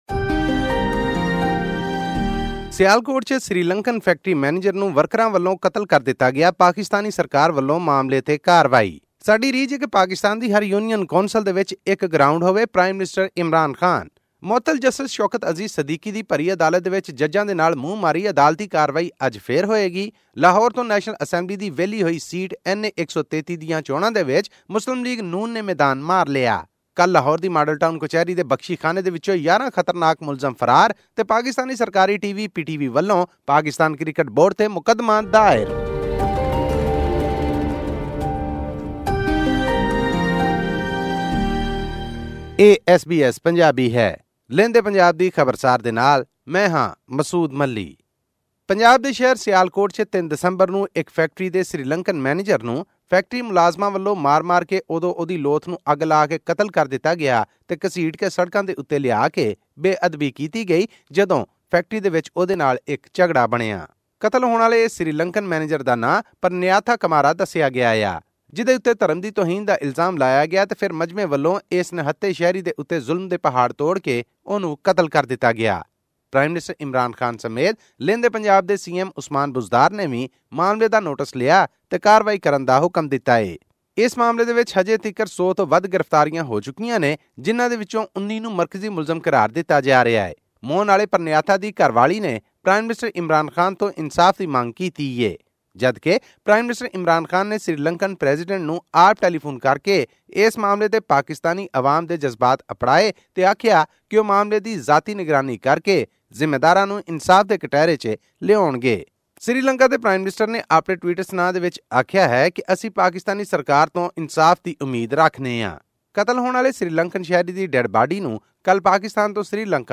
In a bid to reform the country's sports structure, Prime Minister Imran Khan said that the government is making more grounds for youth to make Pakistan a great sporting nation. This and more in our weekly news update from Pakistan.